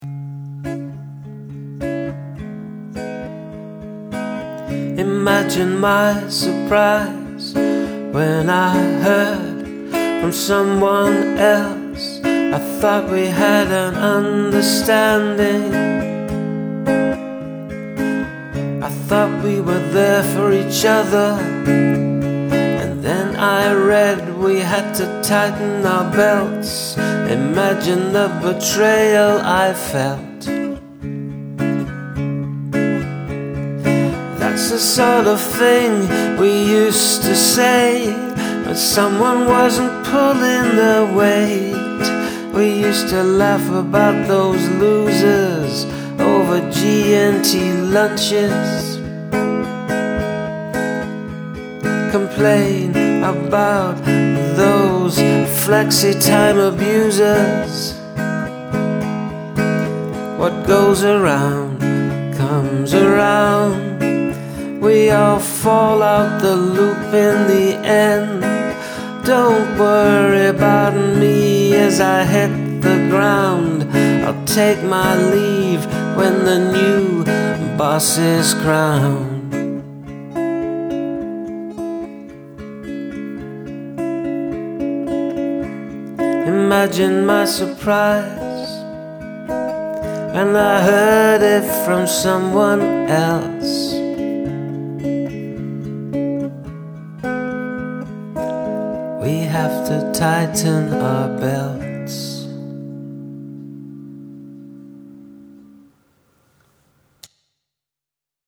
A quick one-take thing because it's quite early in the morning here
This one's got a little kick to it as an Unemploid ™
The tone in both your voice and guitar is just a bit haunting, and that adds a certain something to the emotion in "Imagine my surprise".